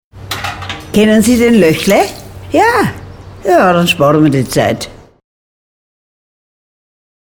Ein bekanntes Küchenhaus in München mit dem Firmennamen Löchle, machte über einen sehr langen Zeitraum Rundfunkwerbung mit der bayerischen Stimme einer in Deutschland sehr bekannten Serien-Schauspielerin (Annemarie Wendel alias Else Kling aus der Lindenstrasse).